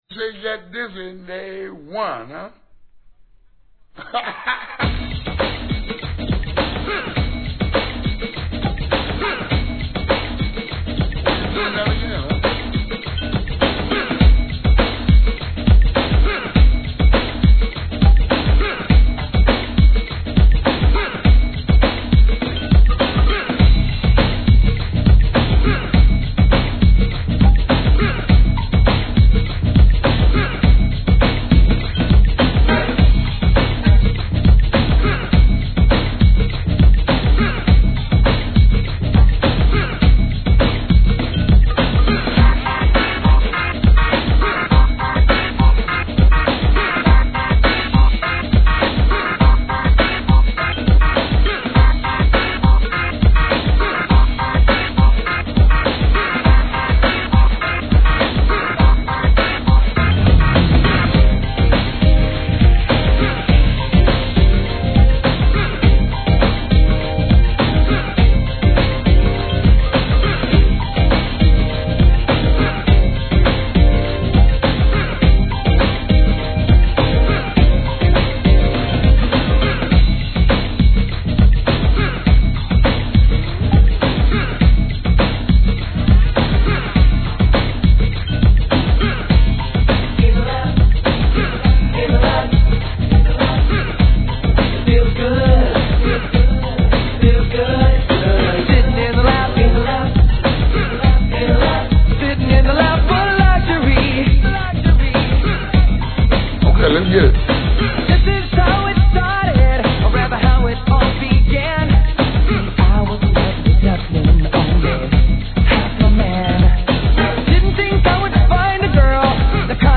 HIP HOP/R&B
NEW JACK SWING